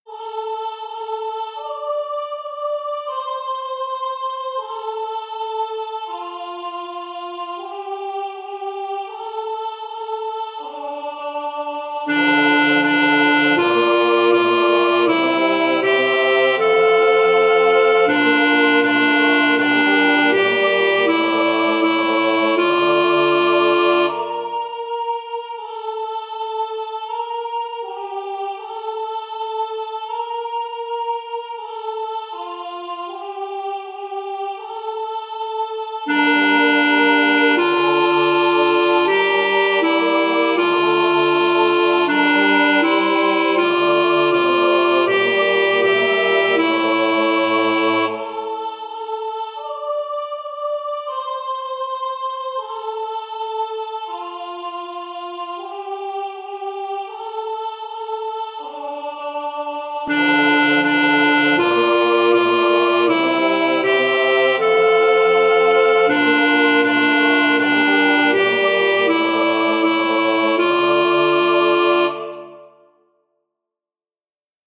Por un lado, los queridos/odiados MIDIS de cada voz.